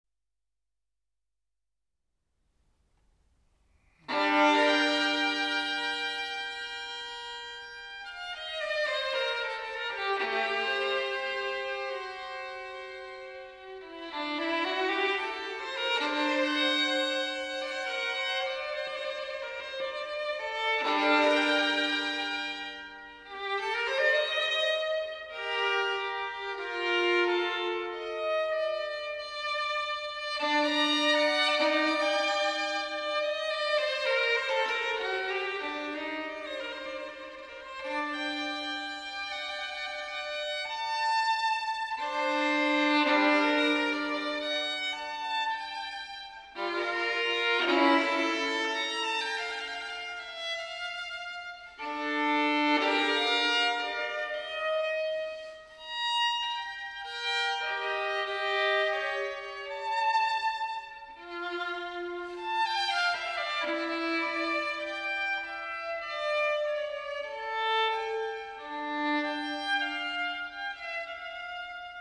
古典音樂、發燒音樂